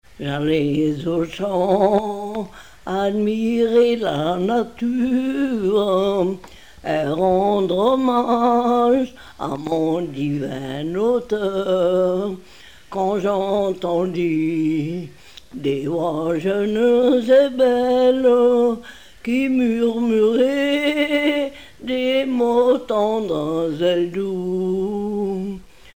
Localisation Mieussy
Nombre de couplets 2 Nombre de refrains 2
Pièce musicale inédite